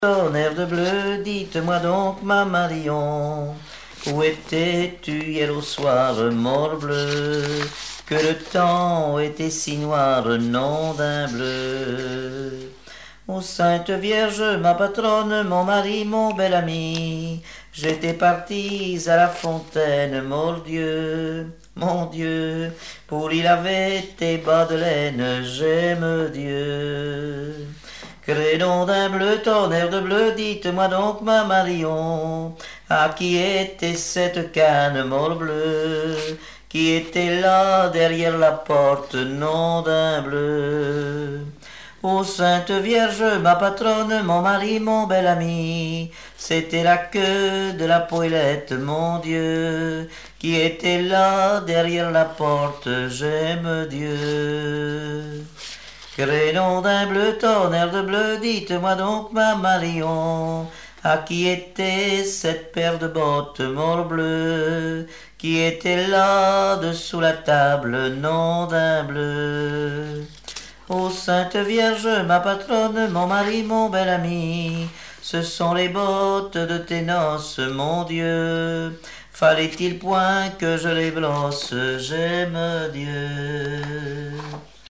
Genre dialogue
Répertoire de chansons traditionnelles et populaires
Pièce musicale inédite